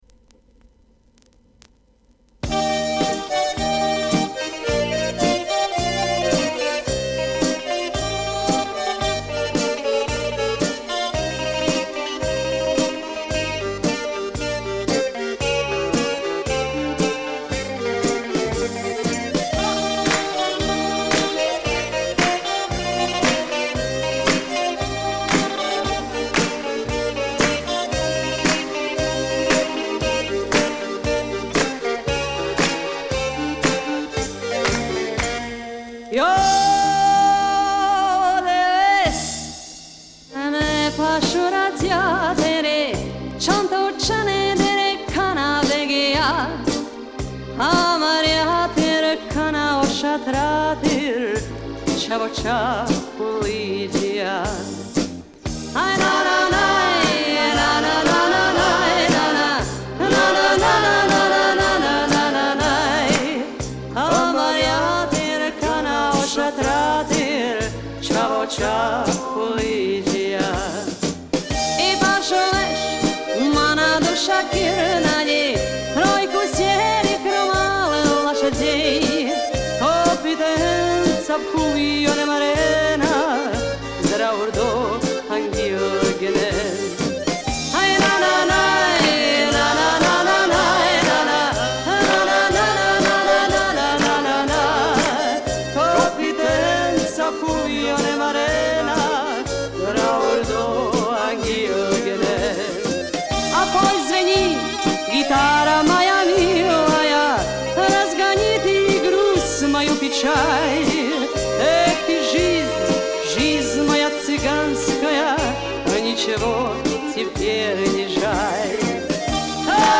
78-rpm
Натуральный звук.